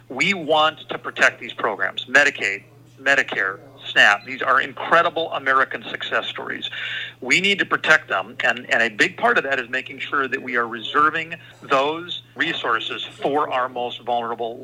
WASHINGTON, D.C.(Northern Plains News)- South Dakota Congressman Dusty Johnson defended the so-called “big, beautiful bill” during a telephone town hall last Wednesday night.